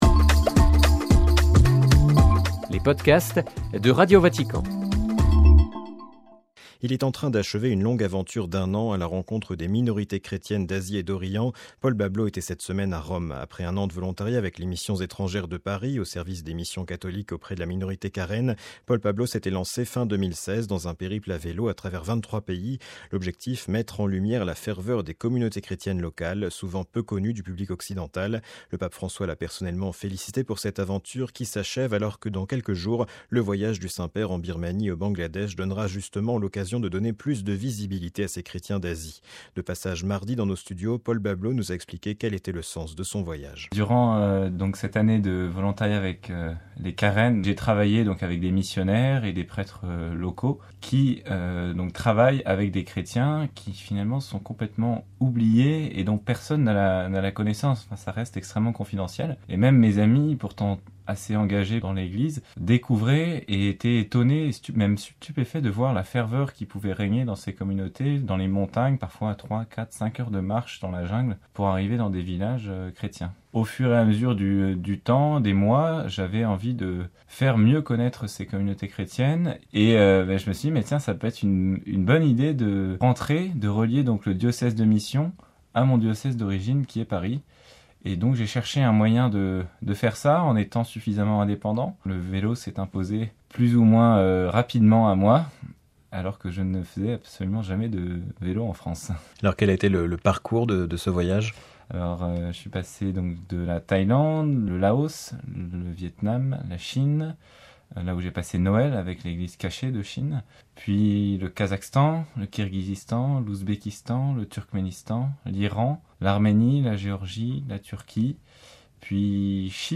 (RV) Entretien